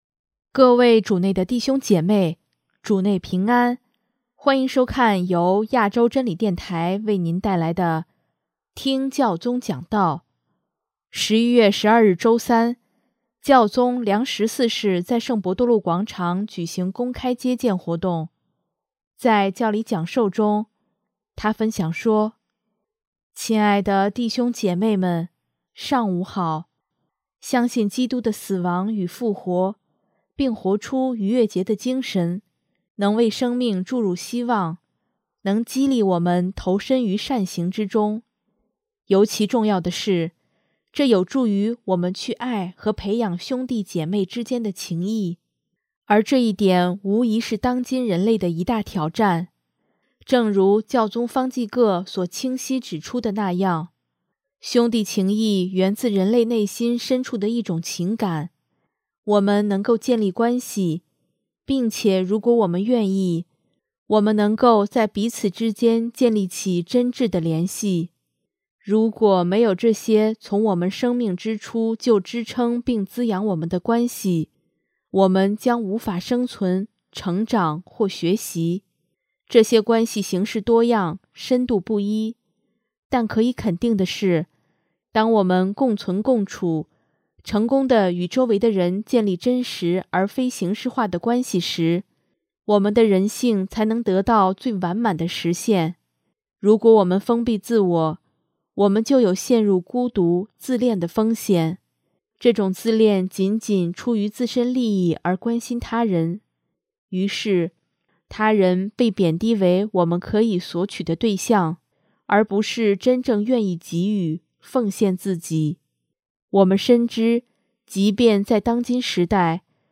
11月12日周三，教宗良十四世在圣伯多禄广场举行公开接见活动。